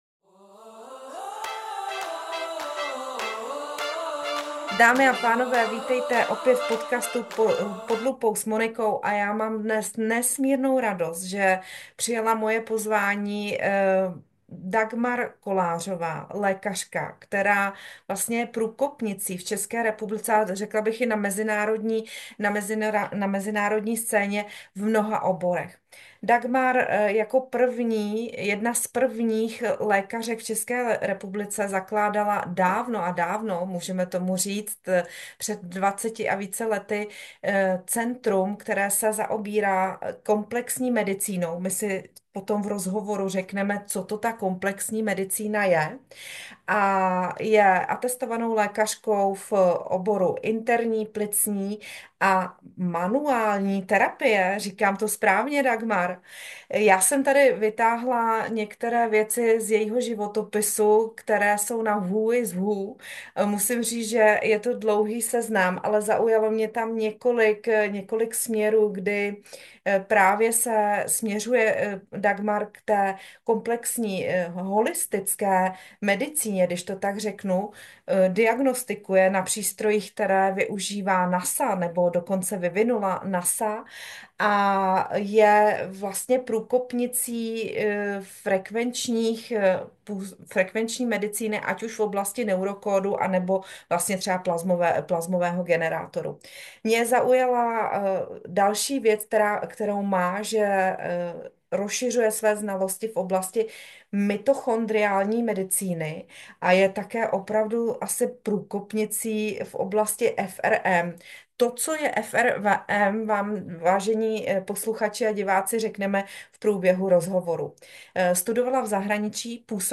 O trendech v oblasti zdraví hovoří v rozhovoru, kde prozrazuje i střípky ze svého studia v zahraničí.